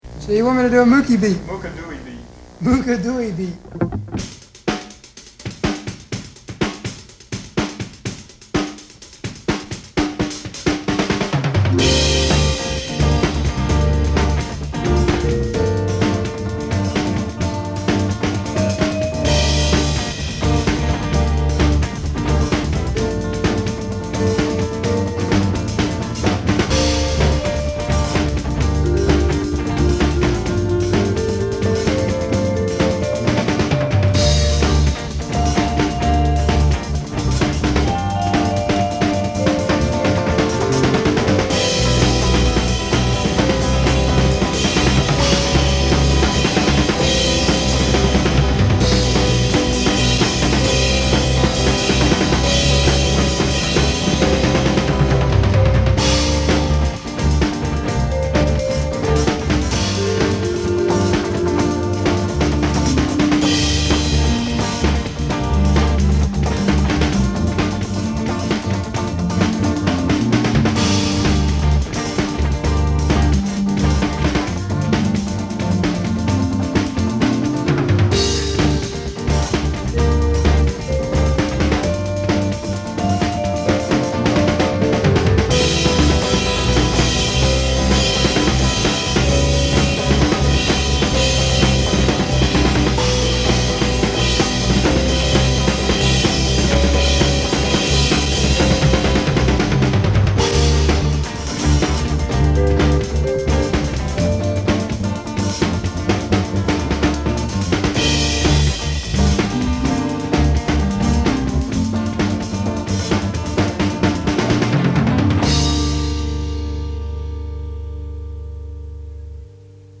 remastered